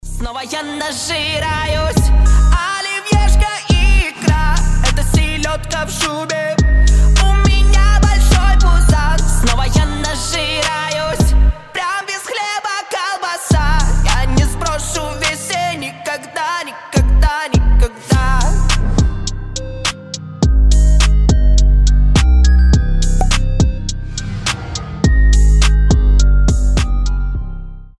веселые
басы
смешные
пародии